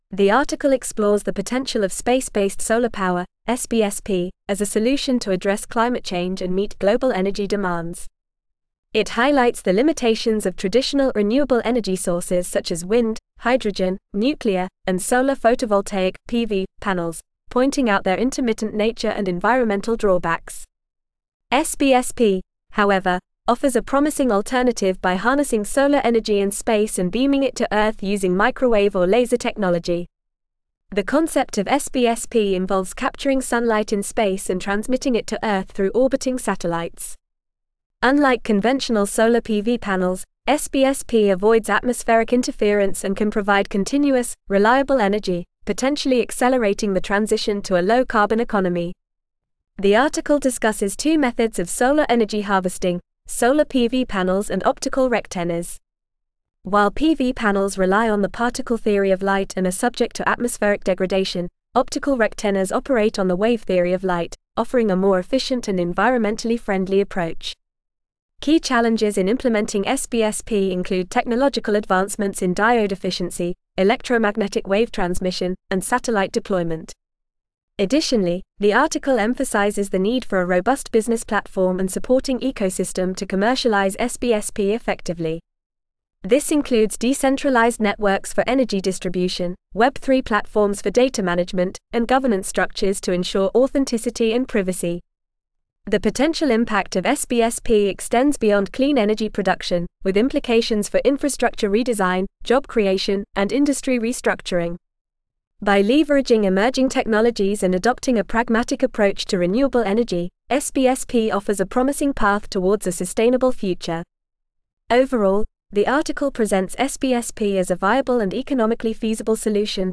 Listen to a short Artificial Intelligence (AI) summary of the following article